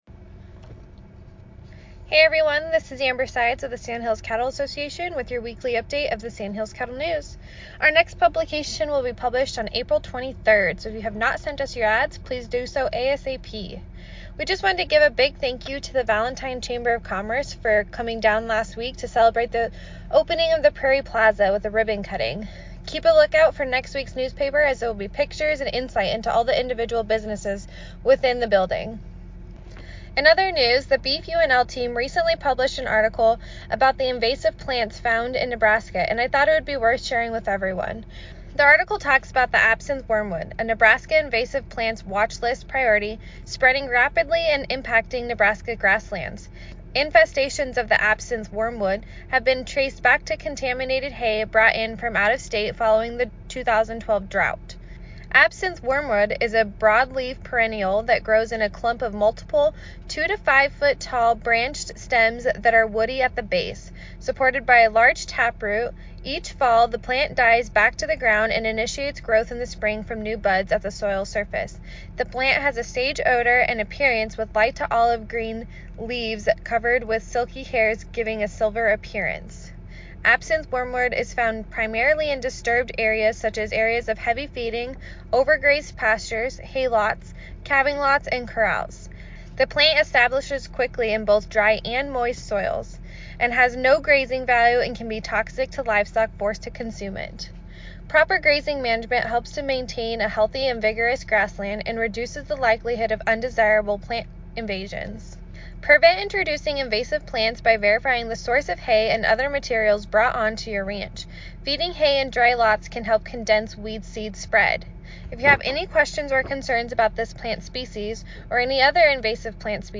SCA Radio Spot on April 15, 2021